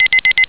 beep.au